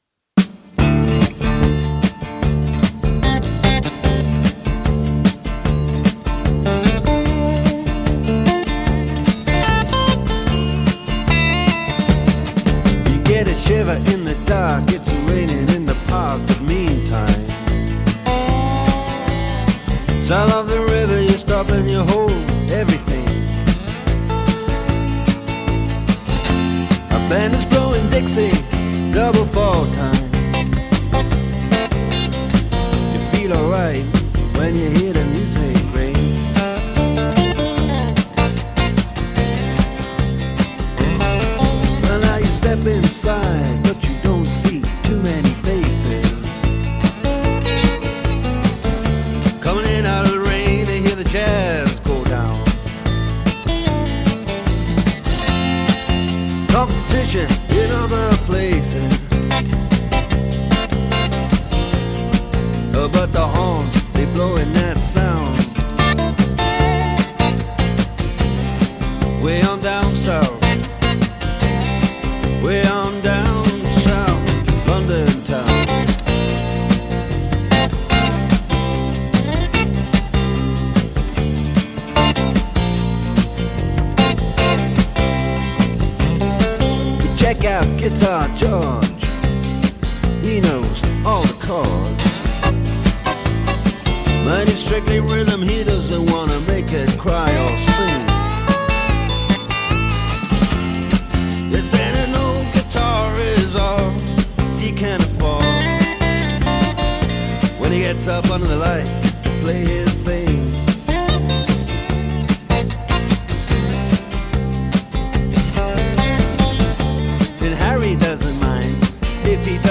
Rock, Pop Rock